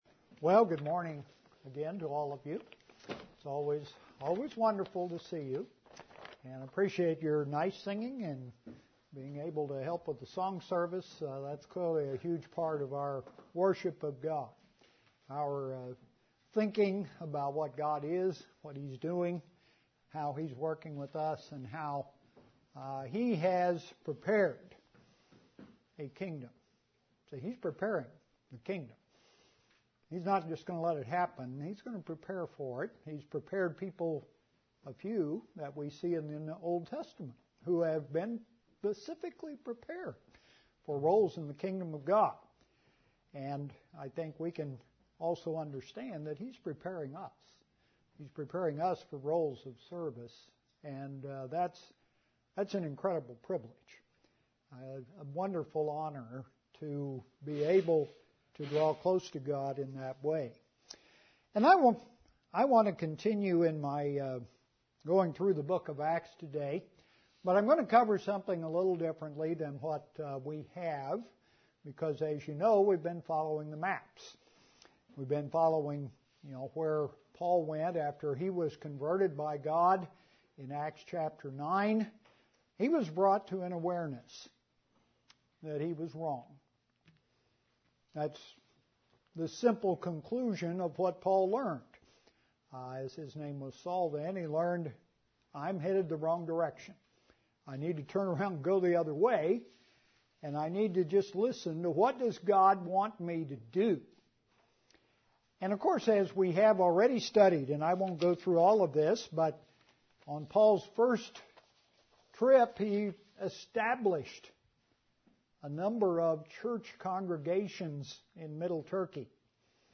A continuation of the sermon series on the Apostle Paul.